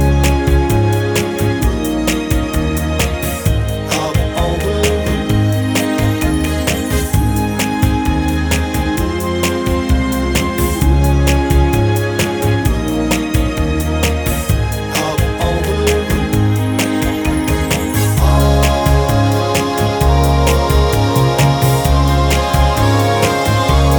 Duet Version Pop (1990s) 2:39 Buy £1.50